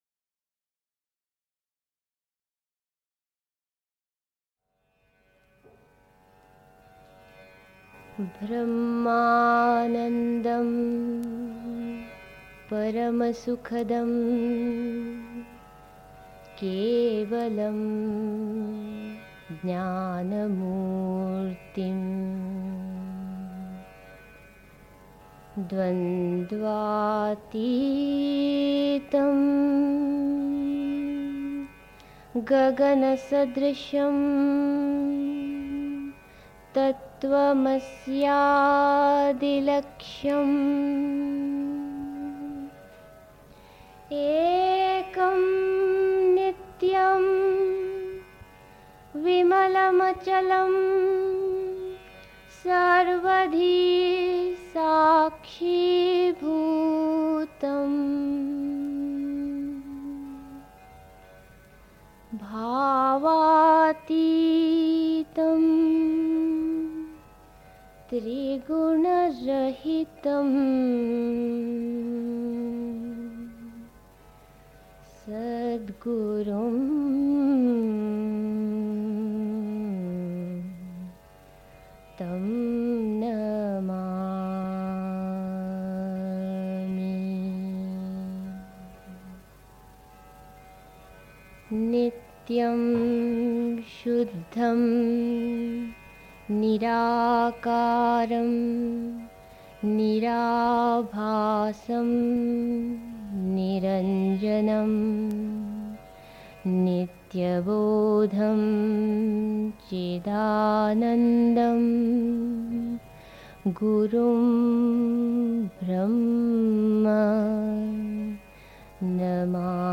1. Einstimmung mit Musik. 2. Hingabe ist der beste Weg des Sich-Öffnens (Sri Aurobindo, SABCL, Vol. 23, p. 603) 3. Zwölf Minuten Stille.